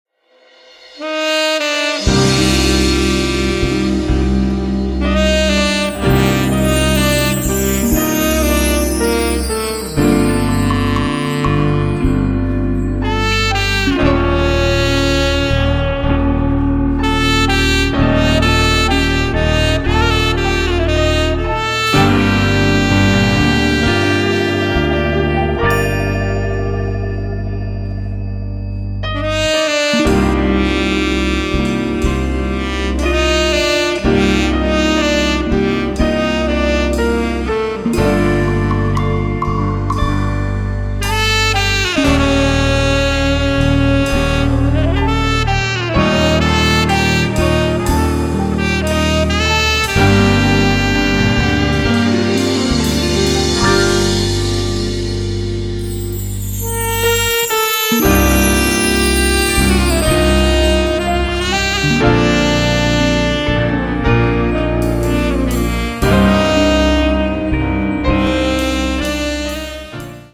sax